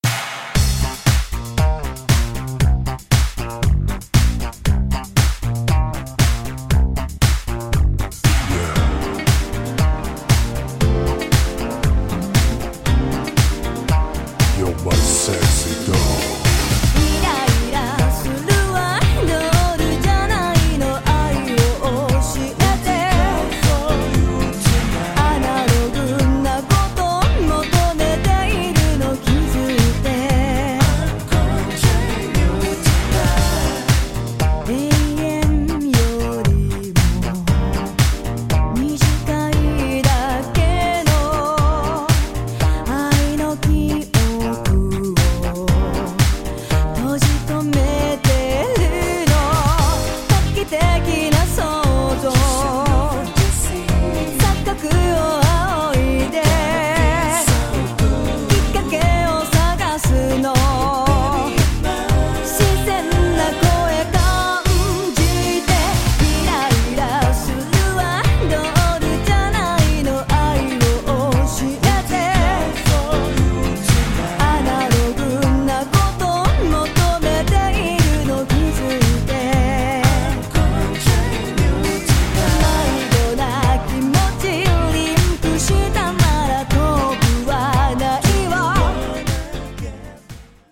グッドな和製ディスコ・ブギー！！
ジャンル(スタイル) JAPANESE POP / DISCO